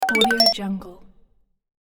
دانلود افکت صدای زنگ فن آوری رابط
به دنبال صداهایی مدرن و تکنولوژیک برای پروژه‌هایتان هستید؟
16-Bit Stereo, 44.1 kHz